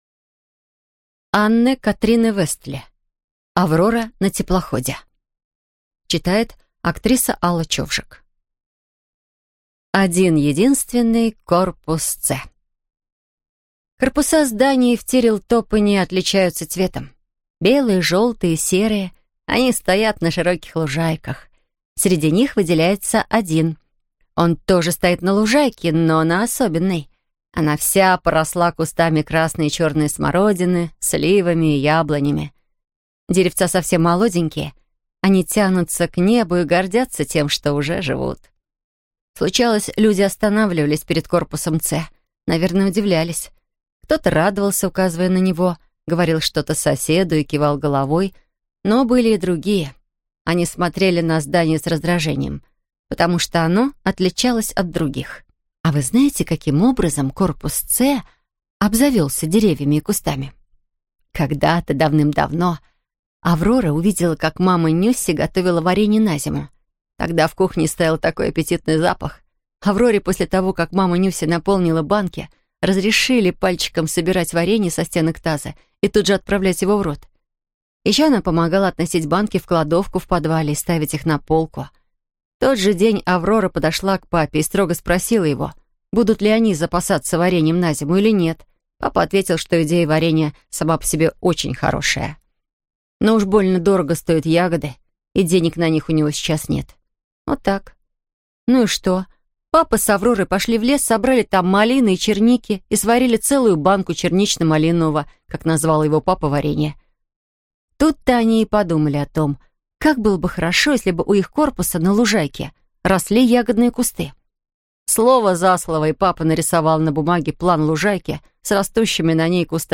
Аудиокнига Аврора на теплоходе | Библиотека аудиокниг